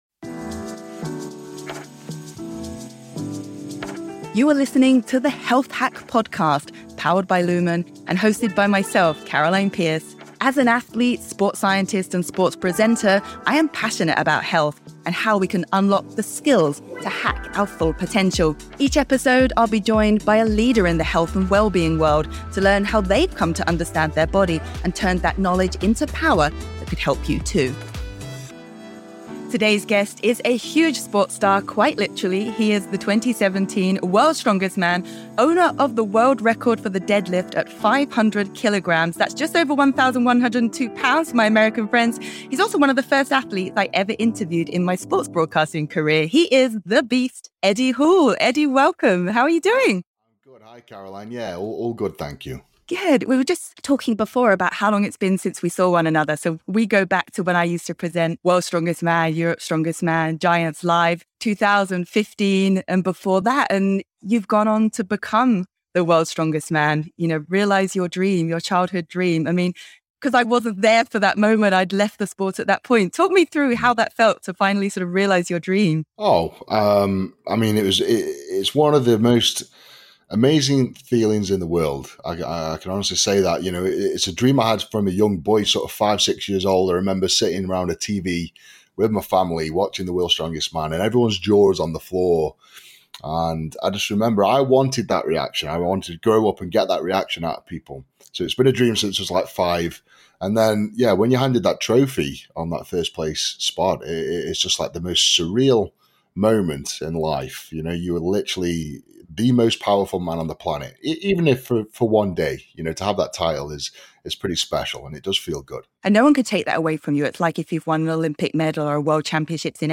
Today’s guest is a huge sports star (quite literally!)